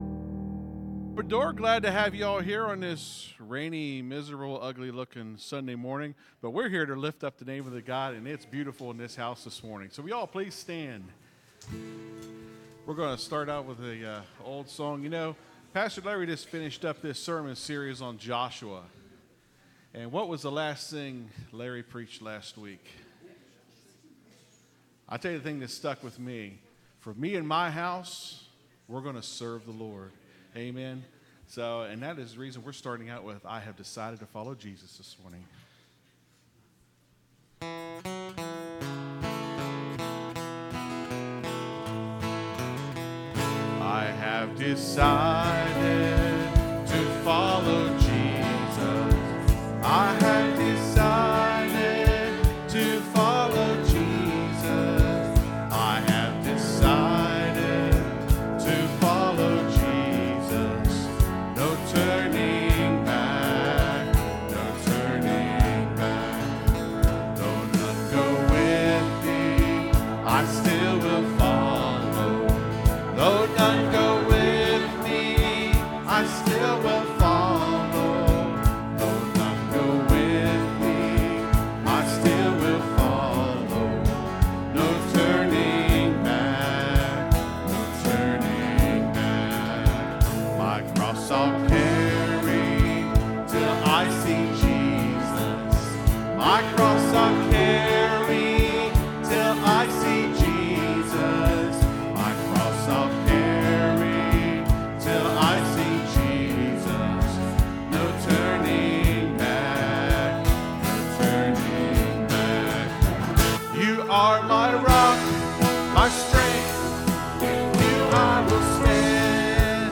(Sermon starts at 23:10 in the recording).